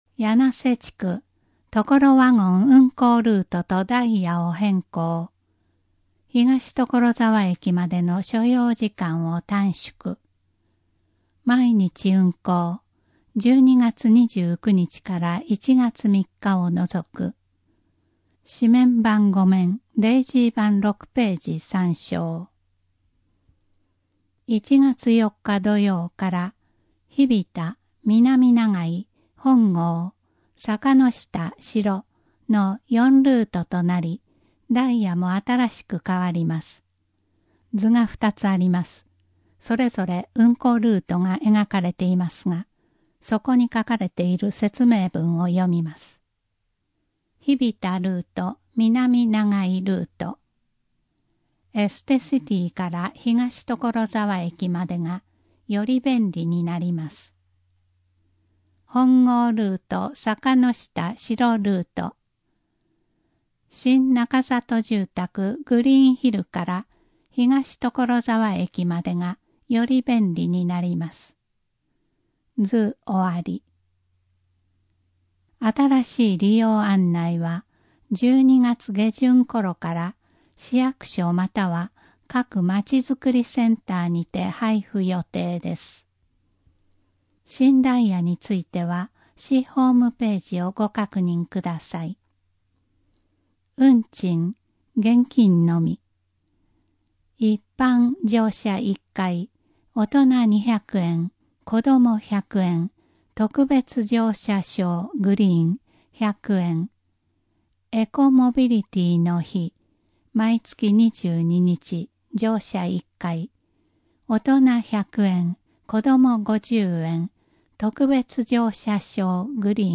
【音声版】広報ところざわ令和6年12月号